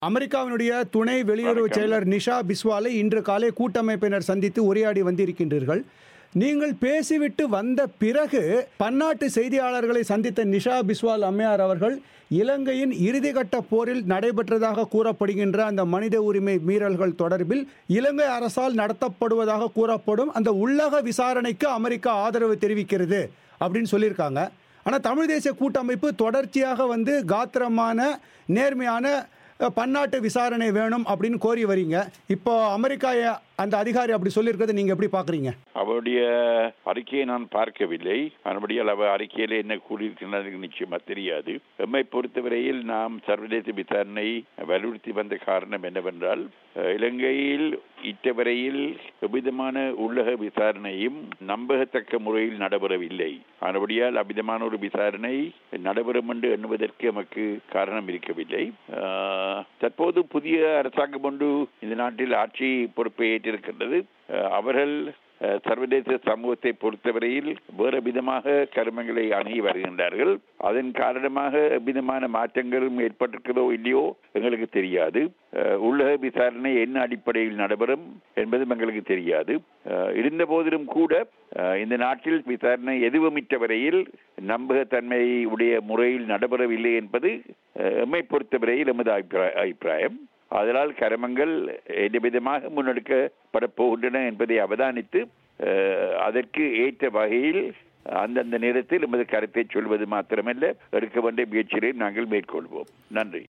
இது குறித்து தமிழ்த் தேசியக் கூட்டமைப்பின் நிலைப்பாடு குறித்து அதன் தலைவர் இரா.சம்பந்தர் தமிழோசையிடம் தெரிவித்த கருத்துக்களை இங்கே கேட்கலாம்.